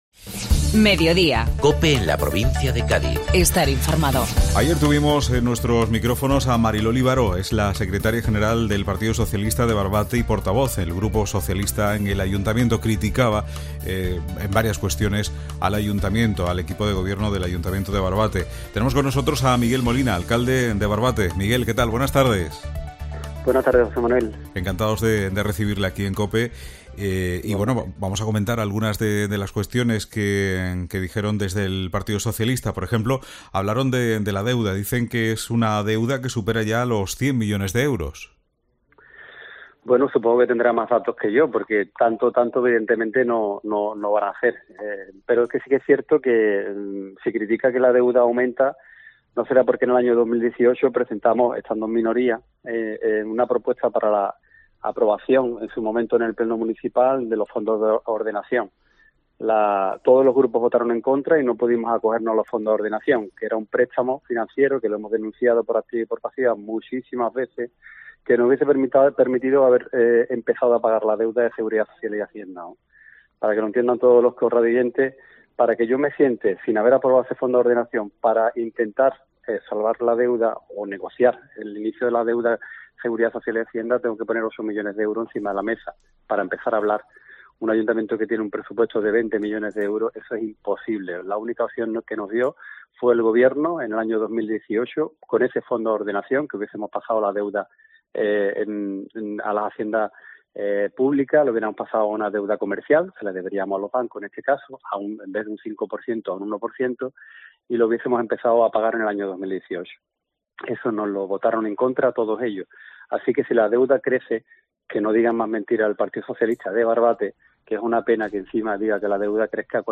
Miguel Molina, Alcalde de Barbate